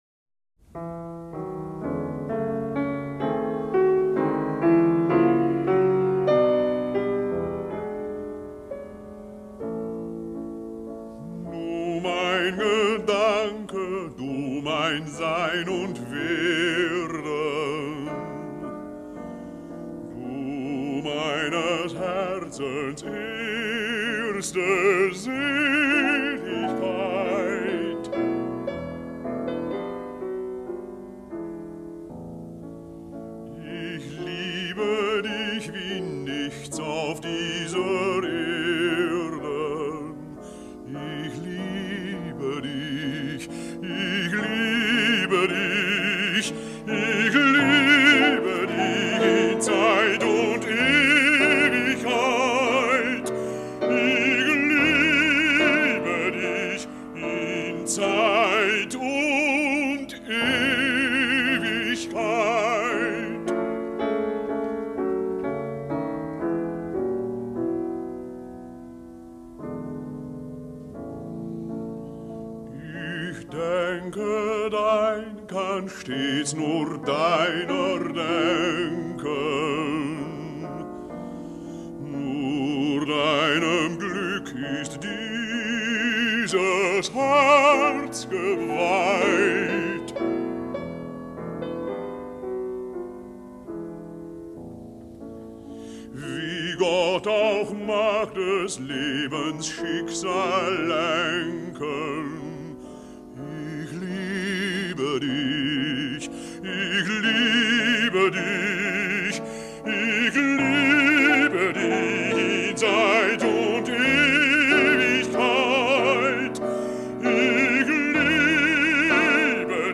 Voicing: Solo Voice